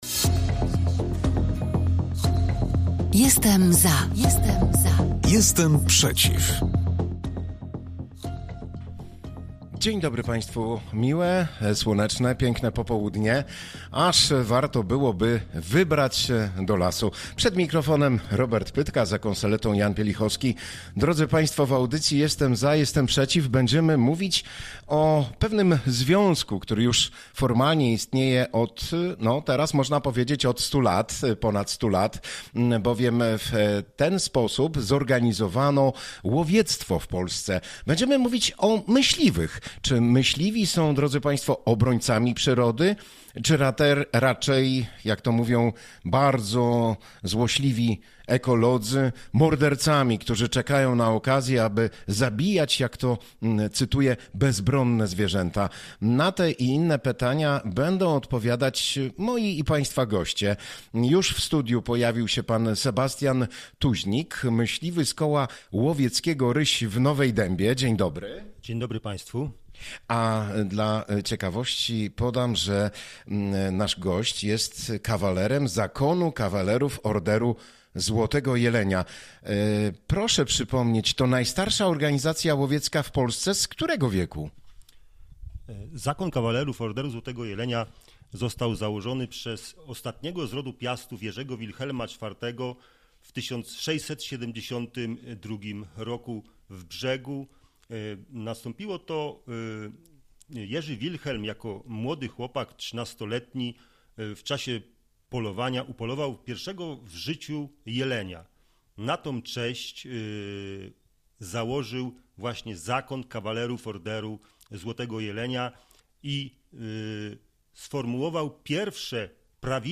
W tym roku mija 100 lat od powstania zorganizowanego łowiectwa w Polsce. Czy myśliwi są obrońcami przyrody? Czy tylko czekają na okazję, by zabijać bezbronne zwierzęta? Na te i inne pytania odpowiadali goście audycji „Jestem za